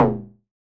RDM_TapeB_SY1-Perc02.wav